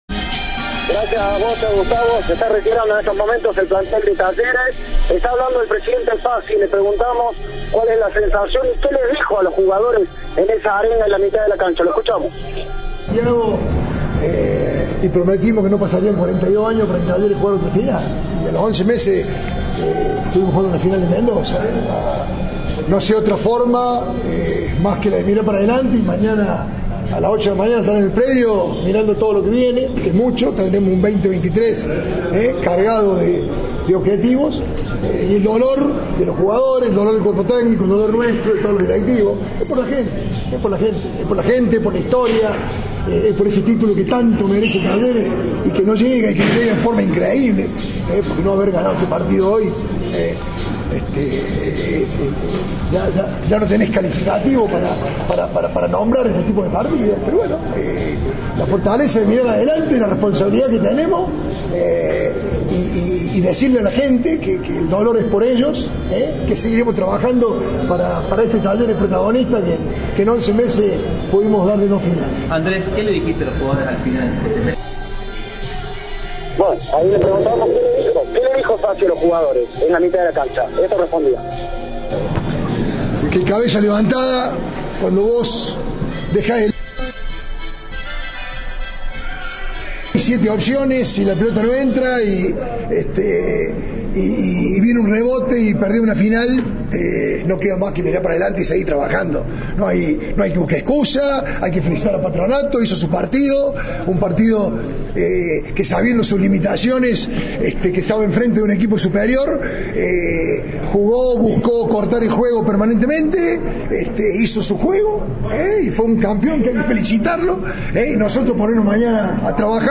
El presidente de Talleres habló tras la derrota por 1-0 contra Patronato y lamentó que la consagración "no llega de forma increíble".